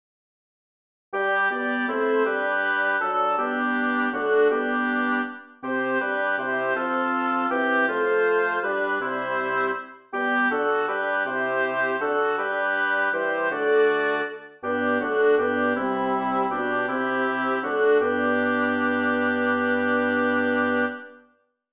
Title: Die Sonne wird mit ihrem Schein a Composer: Melchior Vulpius Lyricist: Michael Weiße Number of voices: 4vv Voicing: SATB Genre: Sacred, Chorale
Language: German Instruments: A cappella